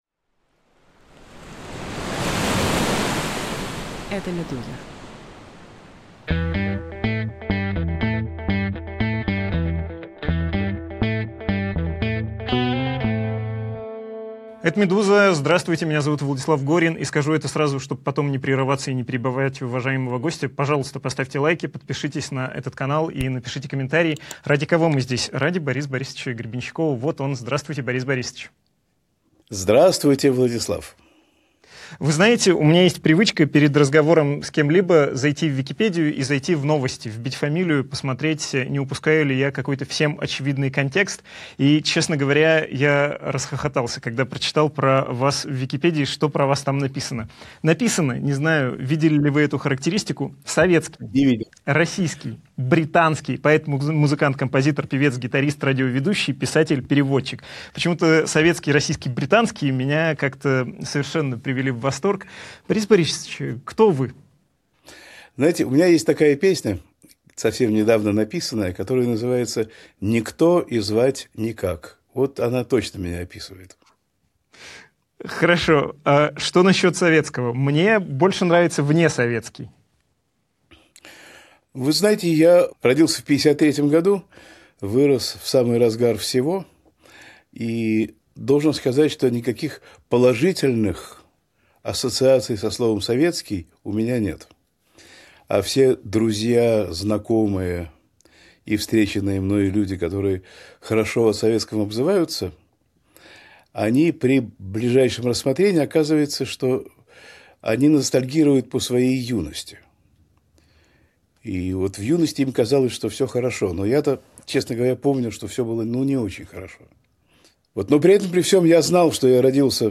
Большое интервью Бориса Гребенщикова о жизни в Лондоне, репрессиях в музыке и, конечно, о смысле жизни
bolshoe-intervyu-borisa-grebenschikova-o-zhizni-v-londone-repressiyah-v-muzyke-i-konechno-o-smysle-zhizni.mp3